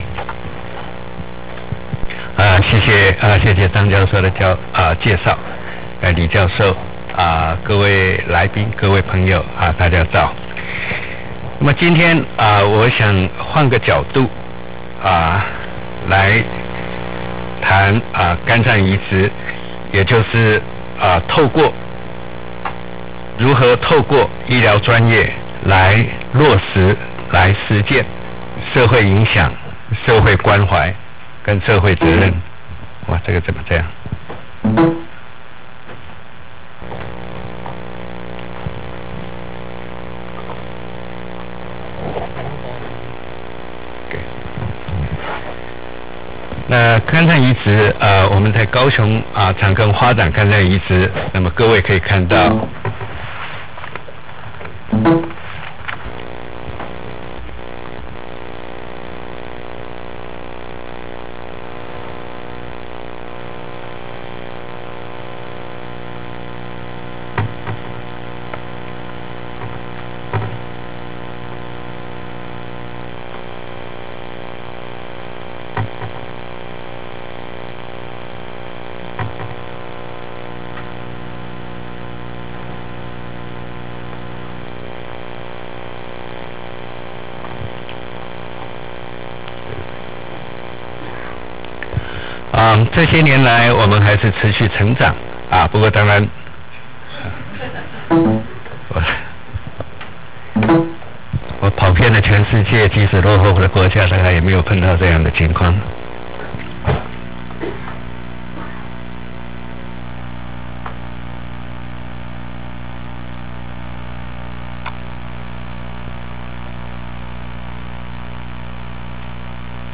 第九屆週日閱讀科學大師專題講座
演講錄音